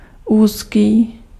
Ääntäminen
Ääntäminen Tuntematon aksentti: IPA: /uːskiː/ Haettu sana löytyi näillä lähdekielillä: tšekki Käännös Ääninäyte Adjektiivit 1. étroit {m} France 2. serré {m} France Muut/tuntemattomat 3. étroite {f} Suku: m .